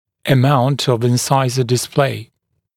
[ə’maunt əv ɪn’saɪzə dɪs’pleɪ][э’маунт ов ин’сайзэ дис’плэй]степень обнажения коронок резцов, степень экспозиции резцов